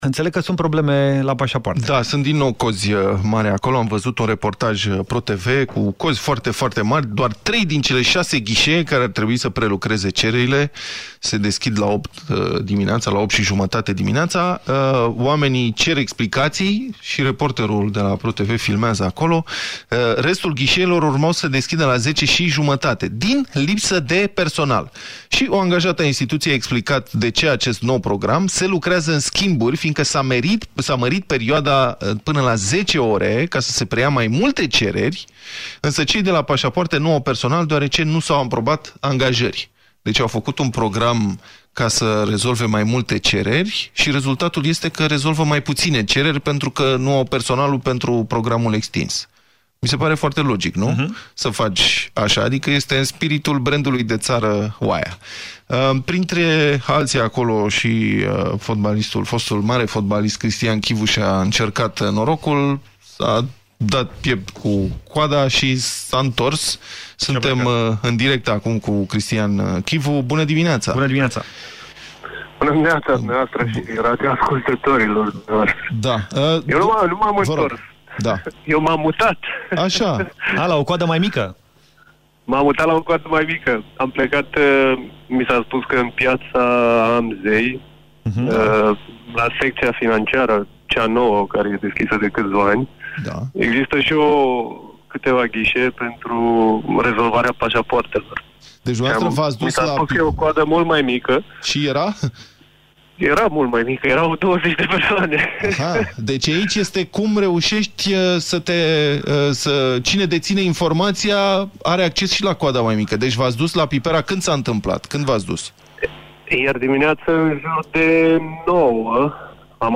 Printre cei care au încercat ieri să îşi schimbe paşaportul ieri dimineaţă în Pipera a fost şi Cristi Chivu, însă a renunţat când a văzut că are numărul 250 pe biletul de ordine.  Fotbalistul a intrat în această dimineaţă în direct şi ne-a spus cum a reuşit să rezolve problema.